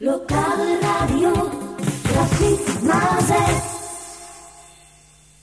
Gezongen jingle